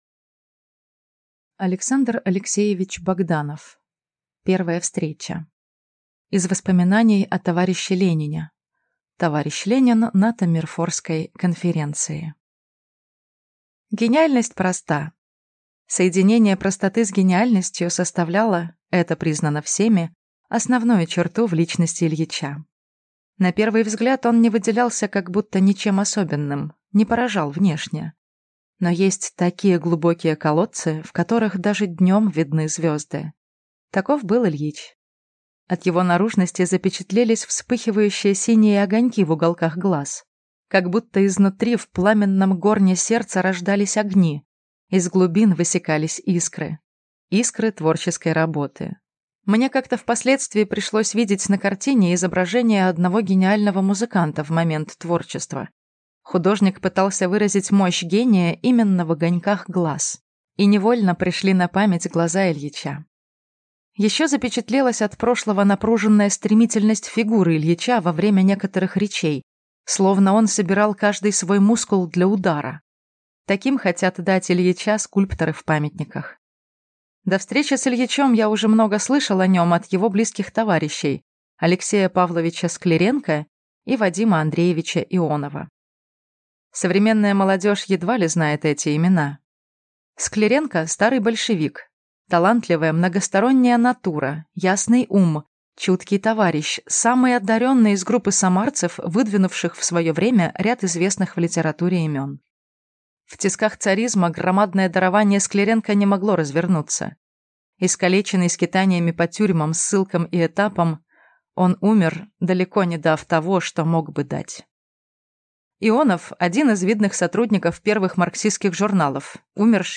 Аудиокнига Первая встреча | Библиотека аудиокниг